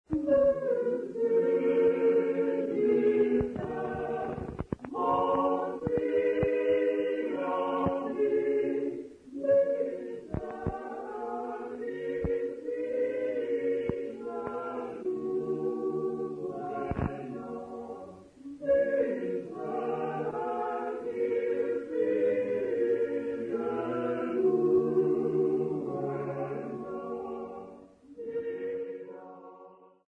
Intshanga church music workshop participants
Sacred music South Africa
Folk music South Africa
Hymns, Zulu South Africa
field recordings
Unaccompanied church hymn.